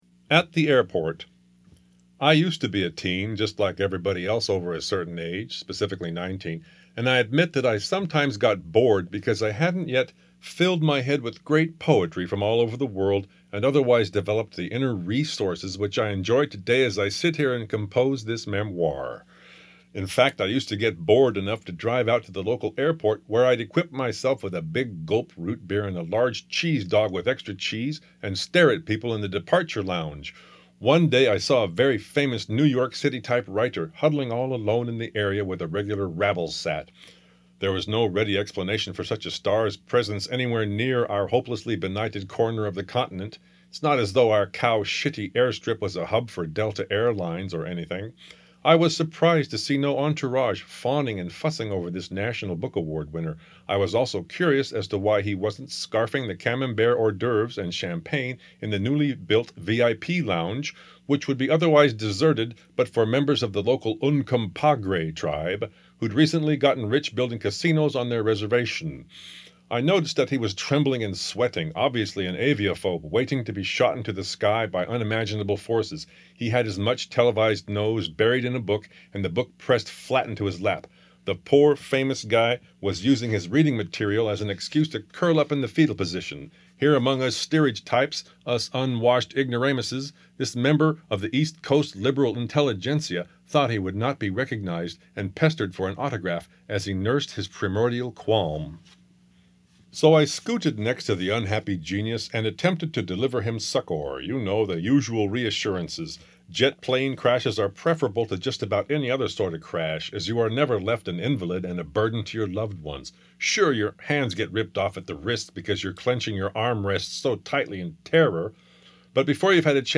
Audio Book from Ahadada Press